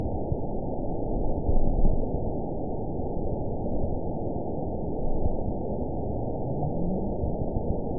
event 919789 date 01/23/24 time 22:44:46 GMT (1 year, 10 months ago) score 9.52 location TSS-AB03 detected by nrw target species NRW annotations +NRW Spectrogram: Frequency (kHz) vs. Time (s) audio not available .wav